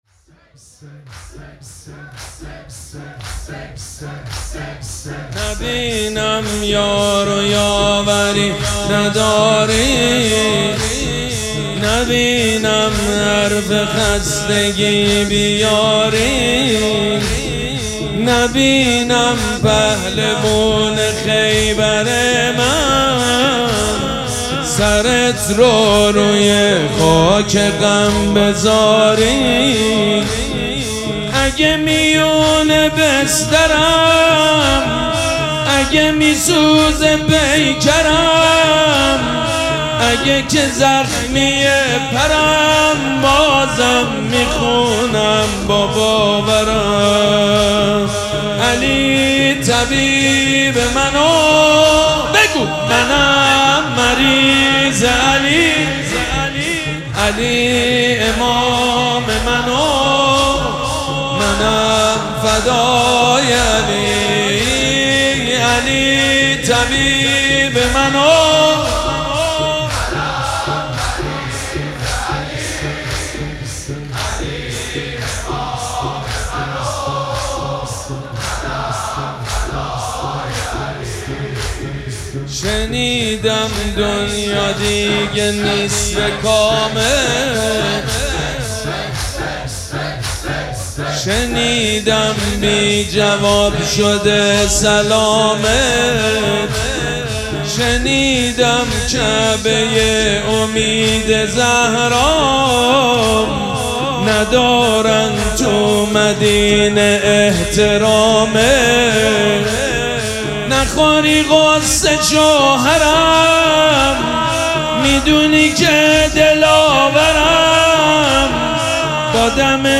شب دوم مراسم عزاداری دهه دوم فاطمیه ۱۴۴۶
مداح